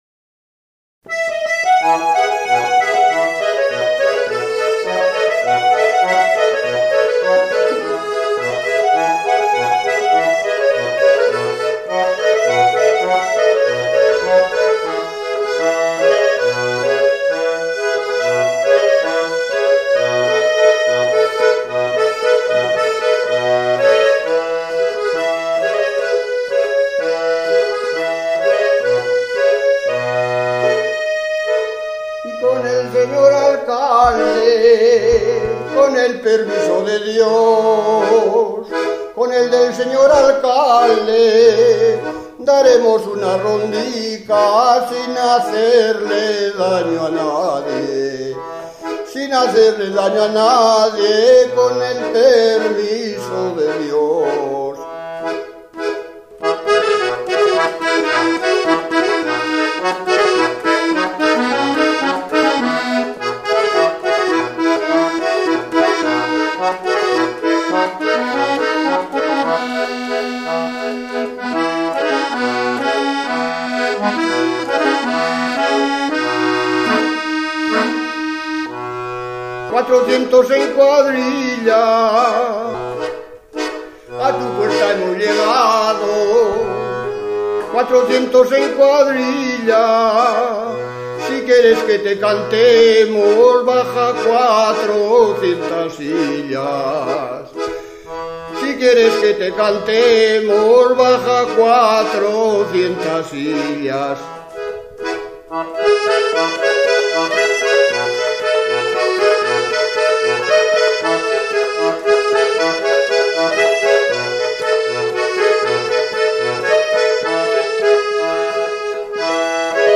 Jotas de ronda VII, con acordeón
acordeón Clasificación: Cancionero Localidad: Villavelayo
al canto
al acordeón